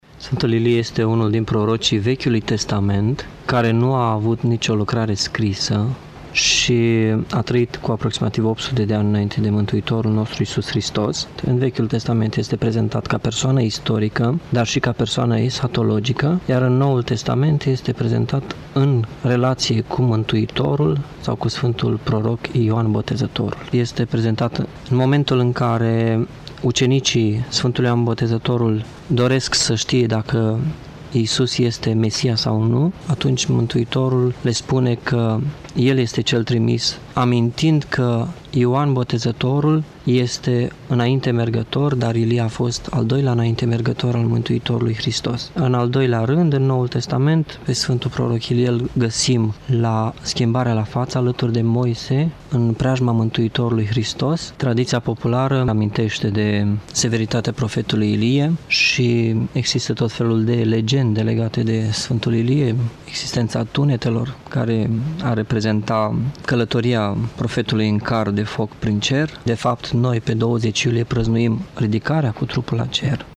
Despre acesta ne vorbeşte părintele conferenţiar universitar doctor